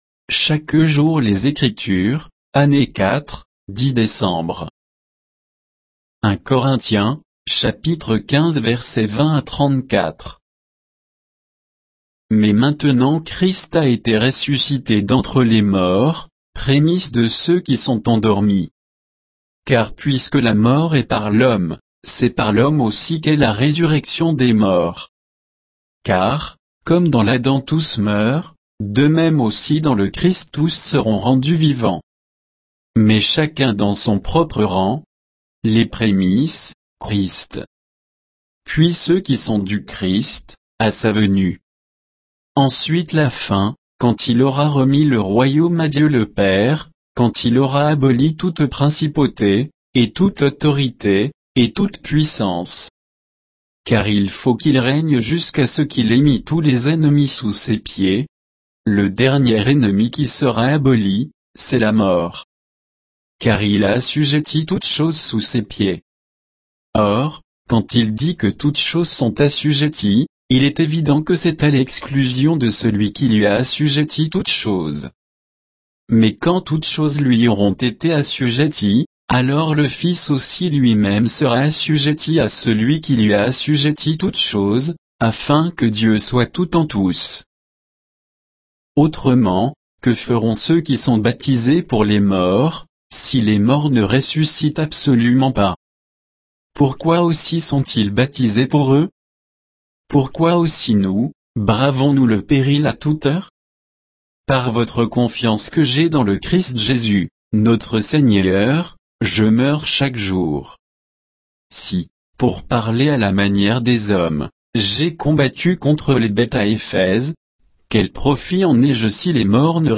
Méditation quoditienne de Chaque jour les Écritures sur 1 Corinthiens 15